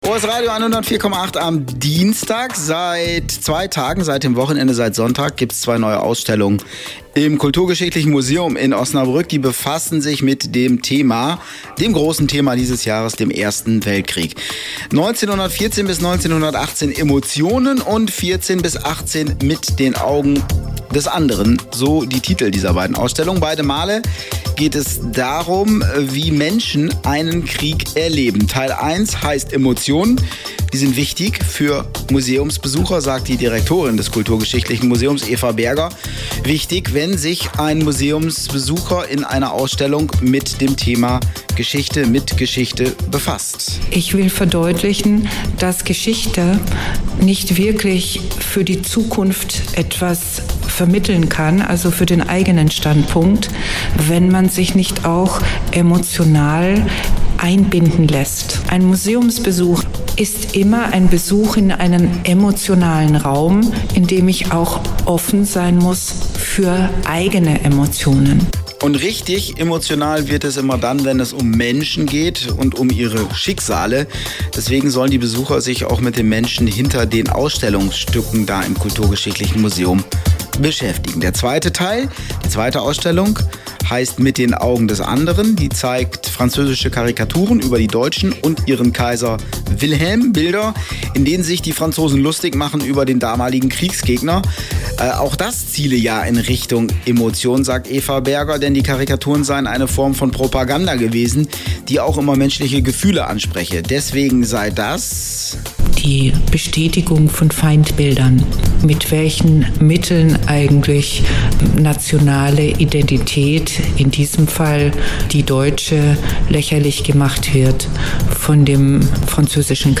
Hörbeitrag: Medien